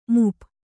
♪ mup